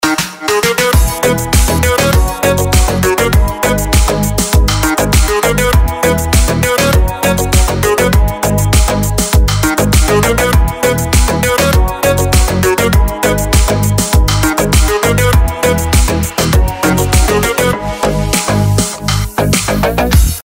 • Качество: 320, Stereo
dance
без слов
Версия без вокала.